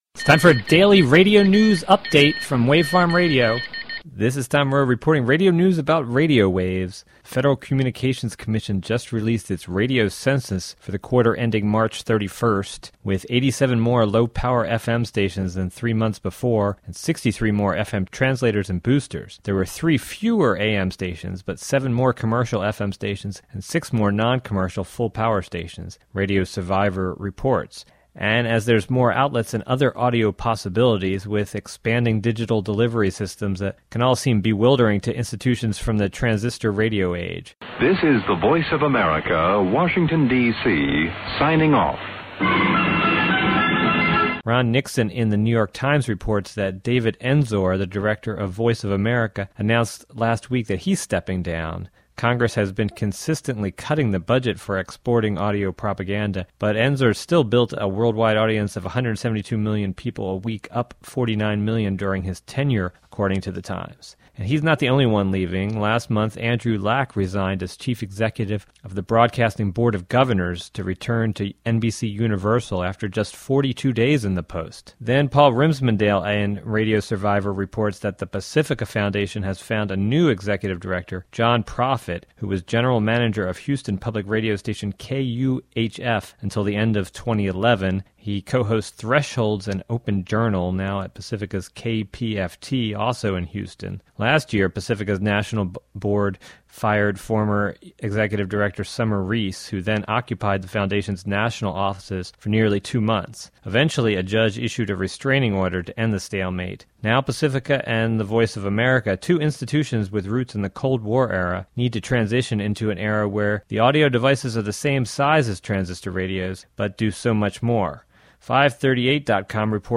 Report about leadership changes at Voice of America and Pacifica Radio, made for April 14, but can run next few days (does not use words such as "today" or "tomorrow" only dates.